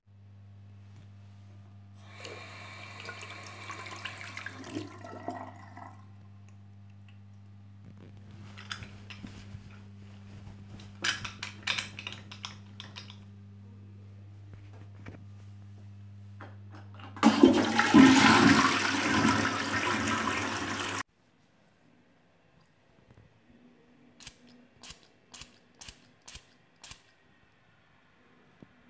Discriminem sons del lavabo
sons-del-lavabo.mp3